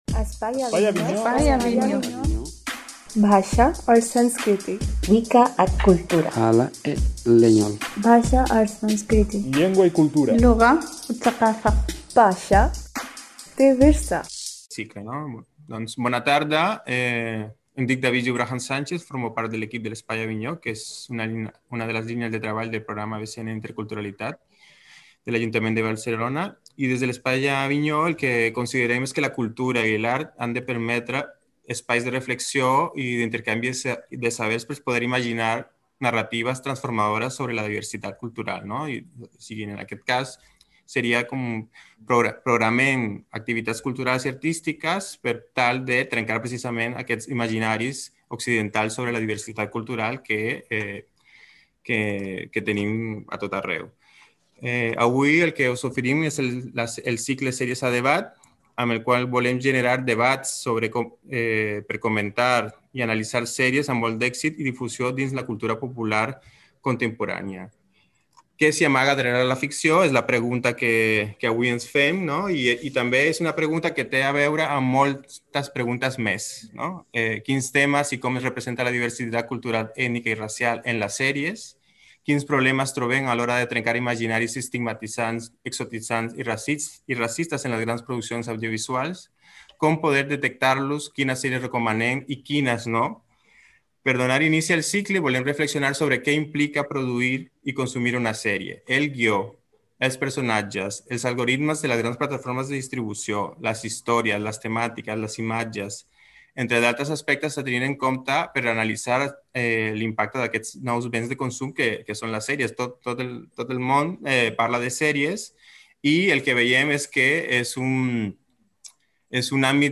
L’Espai Avinyó proposa un cicle de debats per comentar i analitzar sèries amb molt d’èxit i difusió dins la cultura popular contemporània. Quins temes i com es representa la diversitat cultural, ètnica i racial en les sèries? Quins problemes trobem a l’hora de trencar imaginaris estigmatitzants, exotitzants i racistes en les grans produccions audiovisuals?